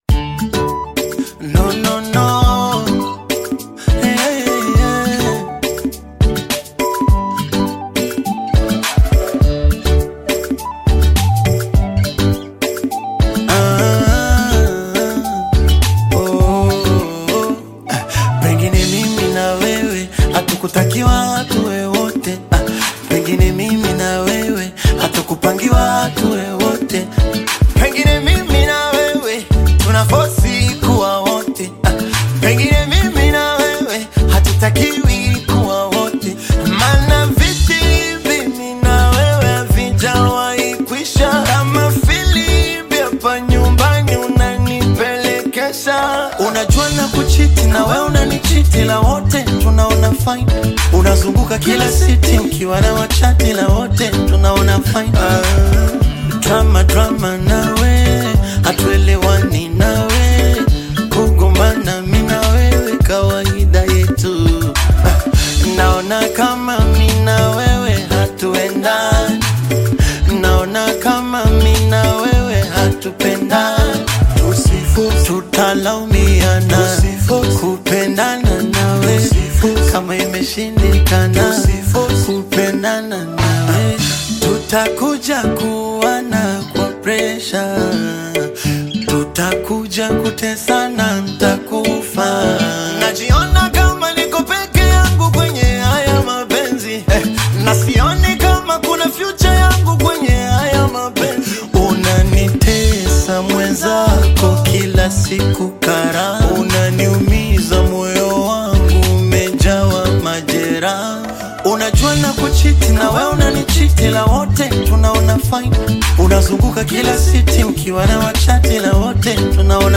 Bongo Flava song